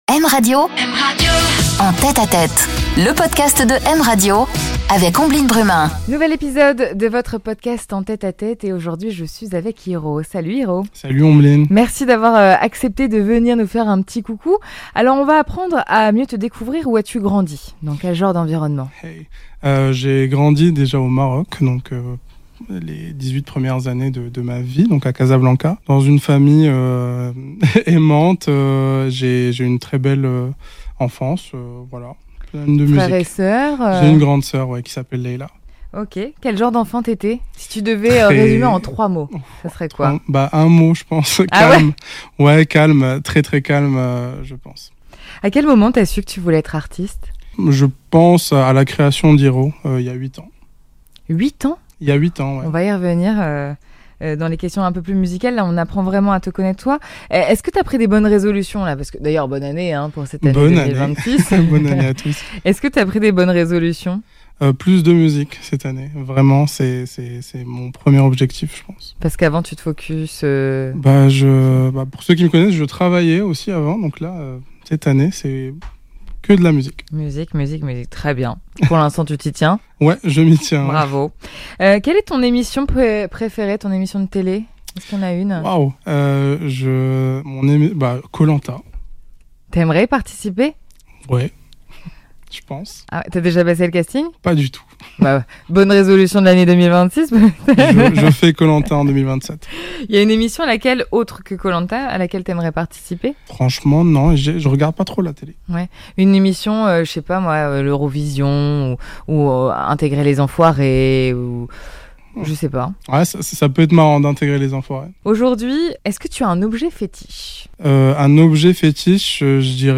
Il nous interprète aussi son titre en live !